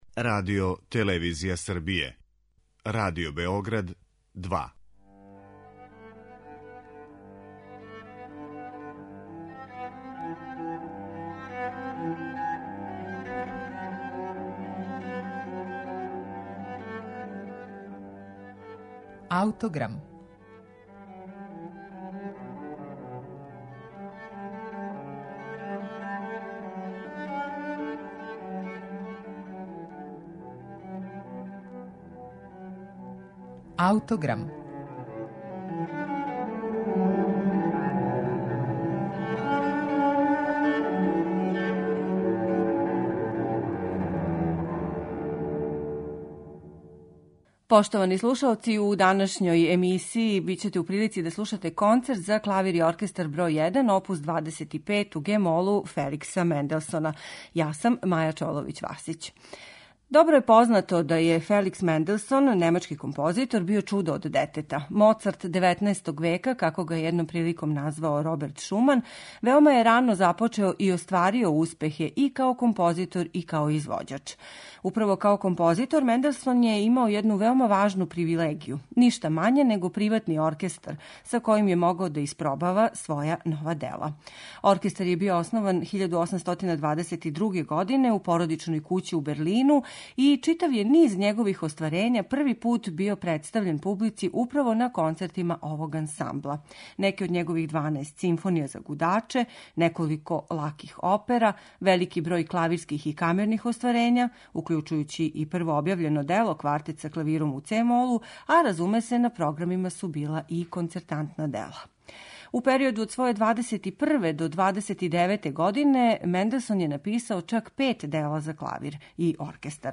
Феликс Менделсон – Концерт за клавир и оркестар бр. 1 оп. 25 у ге-молу